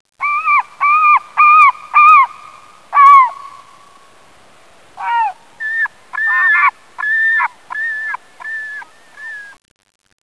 Seagull
SEAGULL.wav